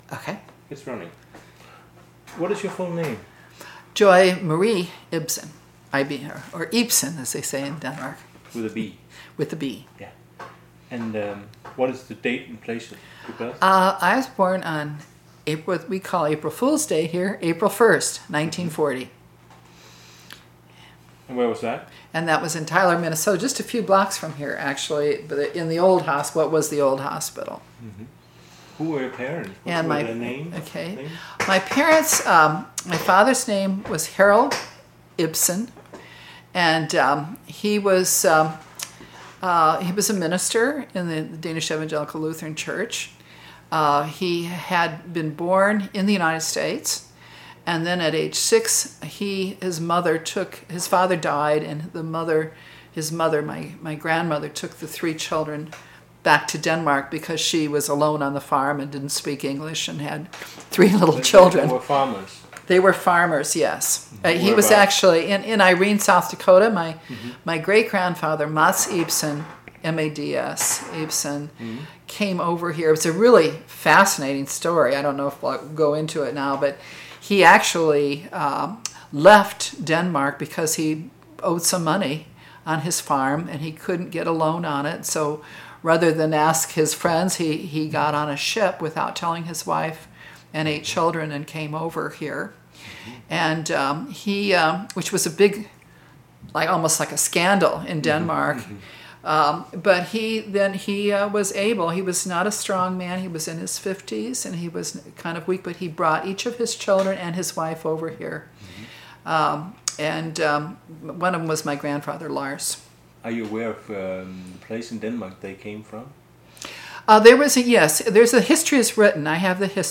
Audio file of interview